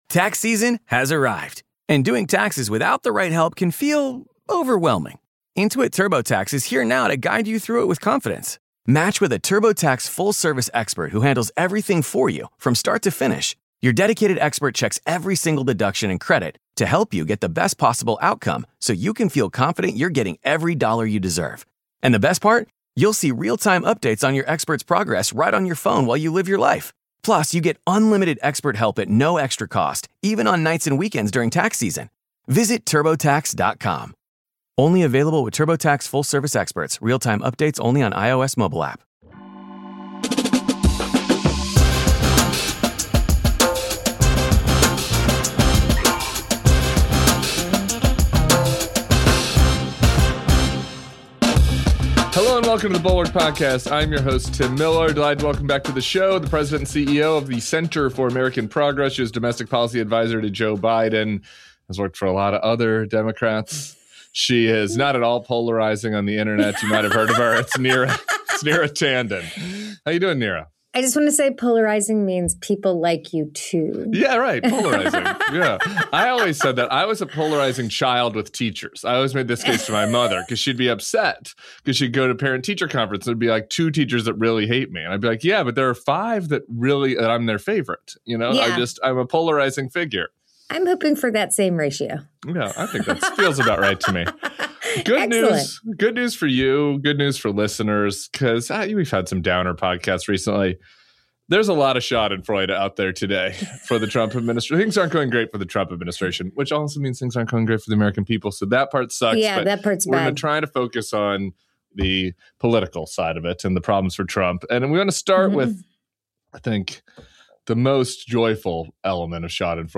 Neera Tanden, with the Center for American Progress, joins Tim Miller for the weekend pod.